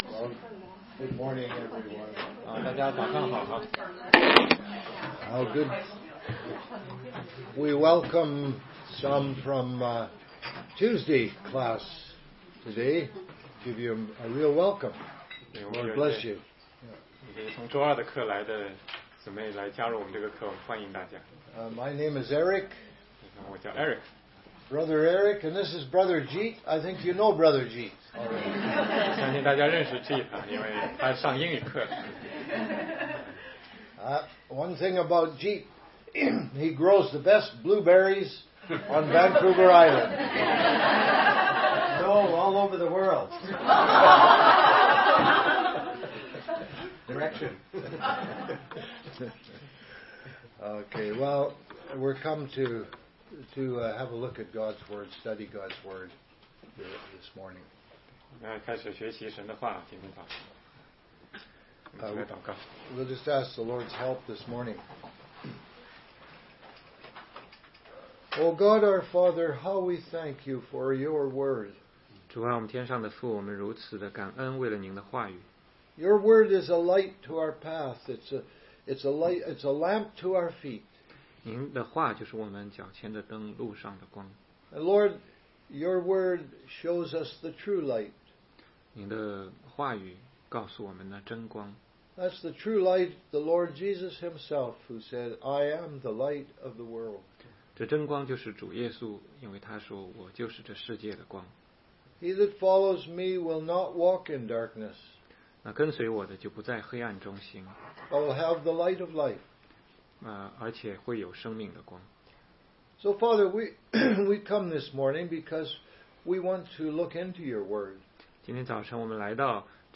16街讲道录音 - 怎样才能读懂圣经系列之十